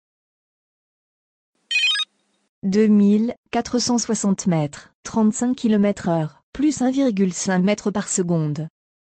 Écoutez simplement la voix de votre nouvel assistant solaire, et volez plus loin.
L’UltraBip parle dans la langue de votre choix (17 langues différentes), et vous donne toutes les informations nécessaires au parapente (altitude, vitesse, taux de montée moyen, heure, cap, durée du vol, et plus encore).
Le tout sans interférer avec les bips du vario, et bien-sûr, comme chaque pilote est unique, tout est personnalisable et désactivable.